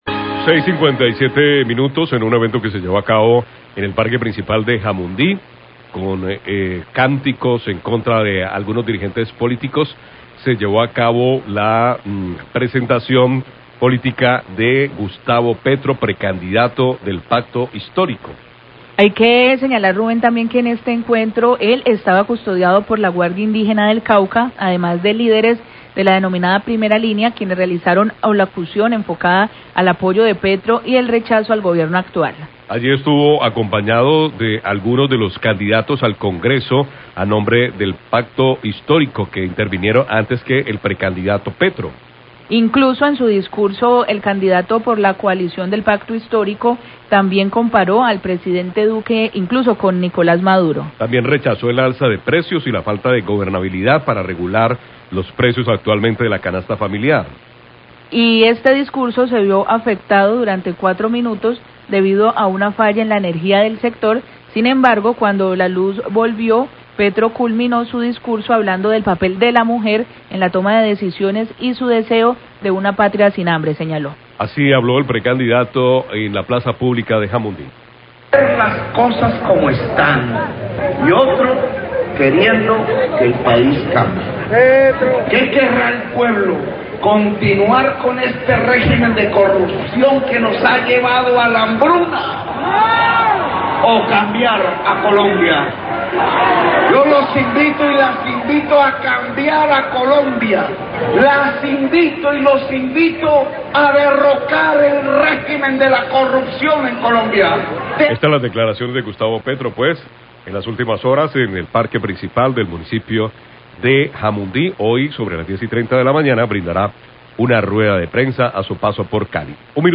Corte de energía durante discurso de Petro en parque principal de Jamundí,
discurso